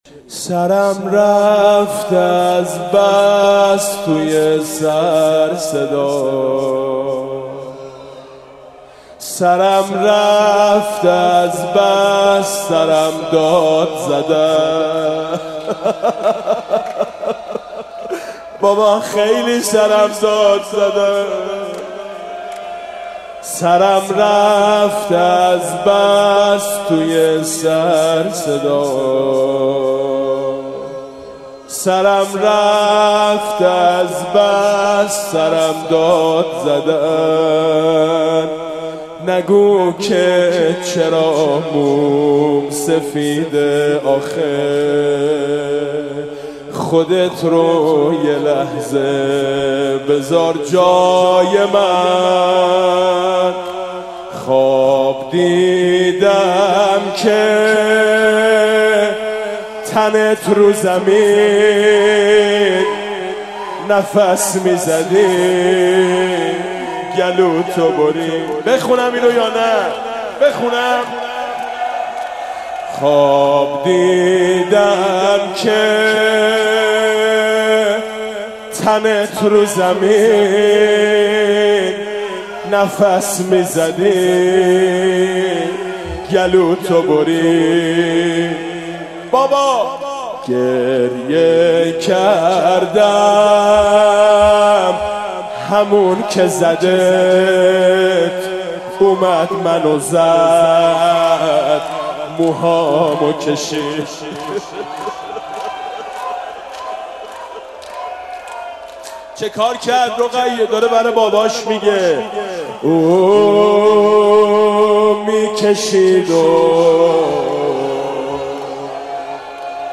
هیئت حسینی نخل کاشان/شب شهادت حضرت رقیه(س)